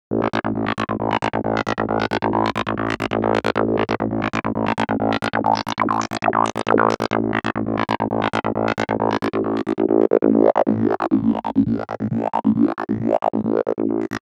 Session 04 - 303 Lead.wav